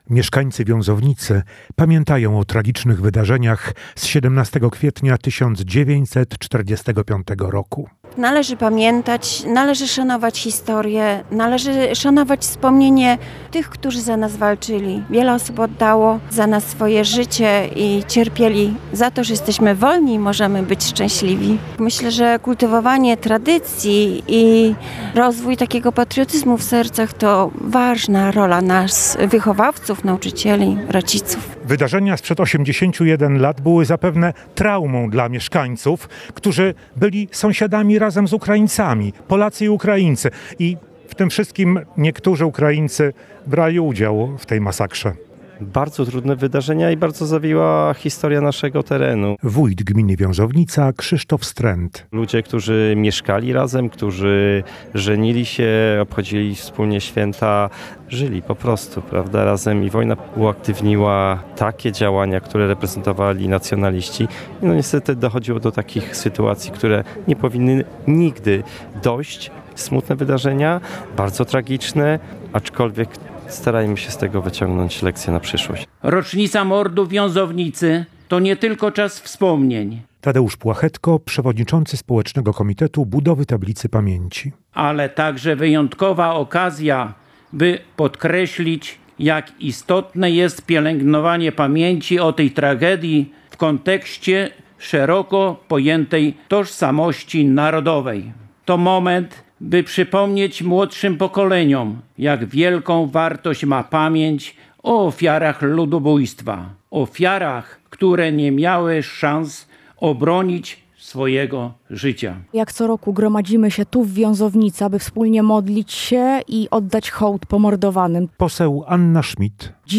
Relacje reporterskie • W Wiązownicy w powiecie jarosławskim odbyły się uroczystości upamiętniające mieszkańców zamordowanych podczas pacyfikacji w 1945 roku.
– powiedział Polskiemu Radiu Rzeszów Krzysztof Strent, wójt Gminy Wiązownica.